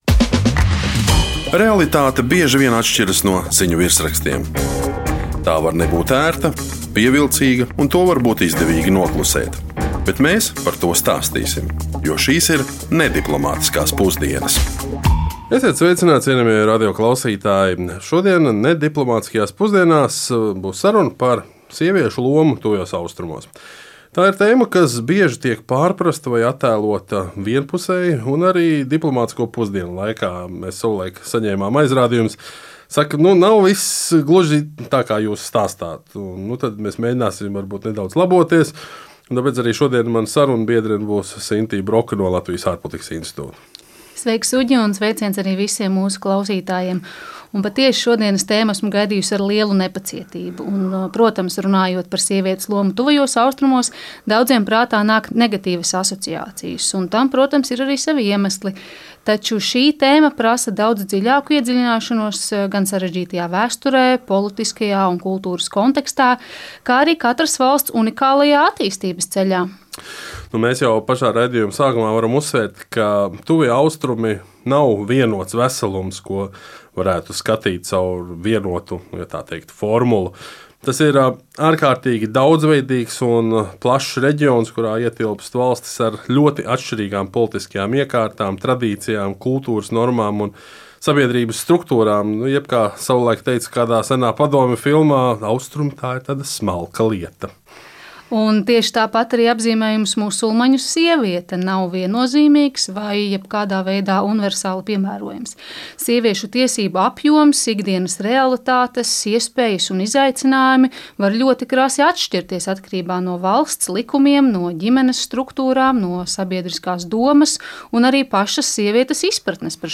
Saruna par sievietes lomu Tuvajos Austrumos.